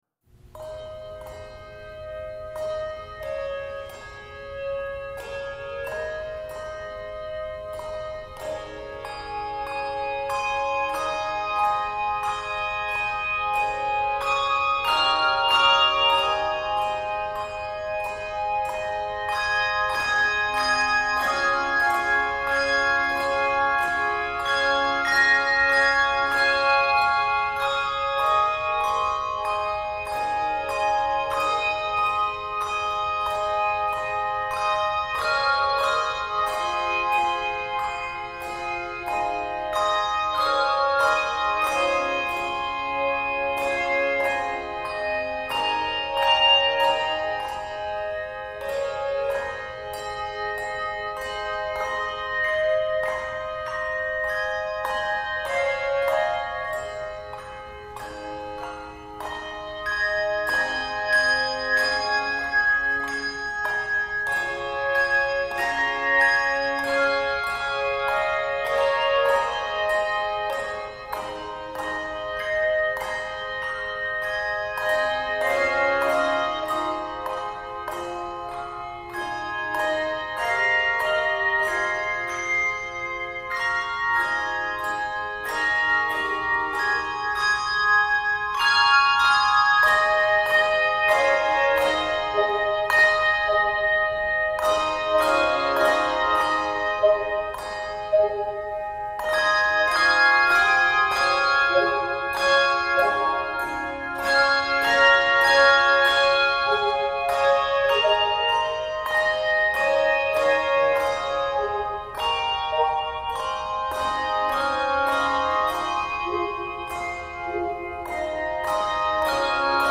Traditional French Carol
Voicing: Handbells